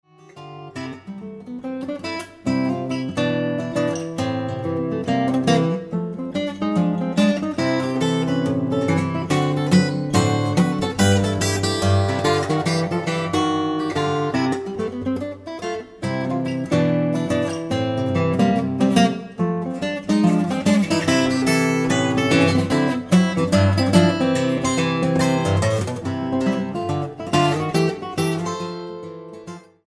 Ragtime Guitarist